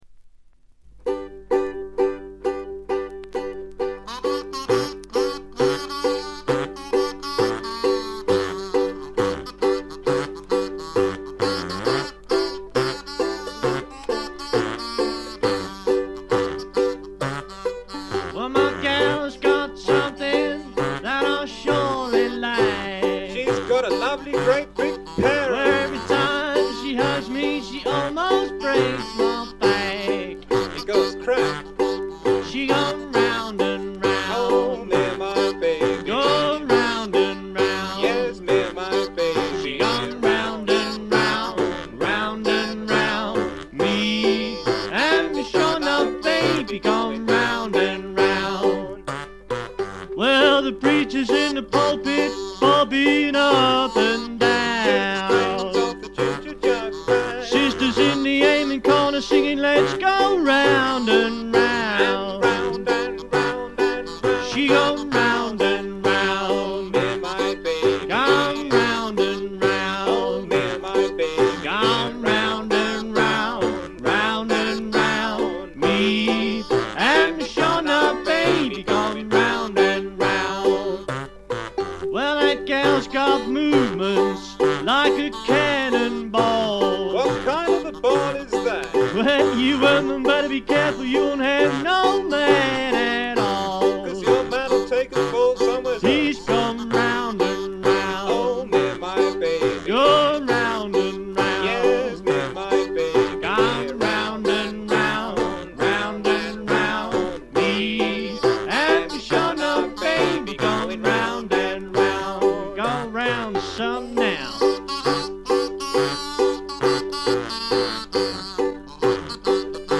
軽微なバックグラウンドノイズのみ。
試聴曲は現品からの取り込み音源です。
lead vocals, kazoo, guitar, banjo, bass drum
washboard, harmonica, jug, hoot